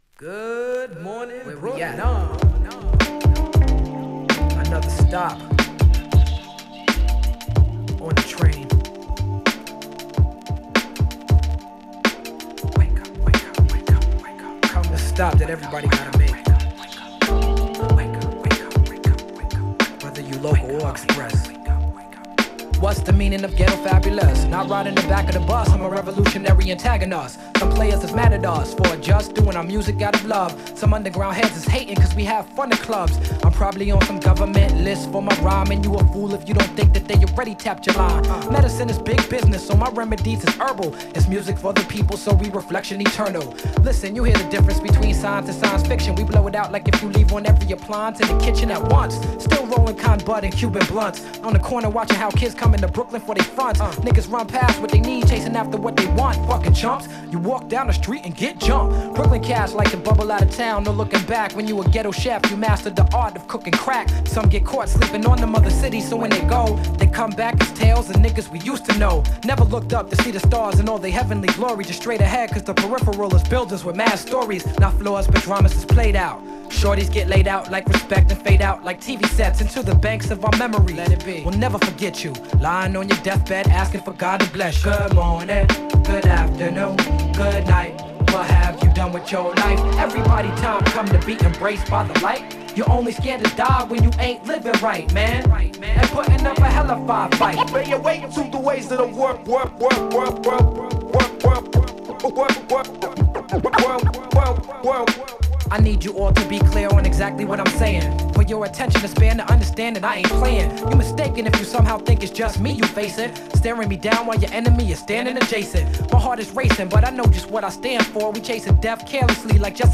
> HIPHOP
玄人向けに、煙いフィルターを被せ、幻想的な夢を彷徨う空間を演出する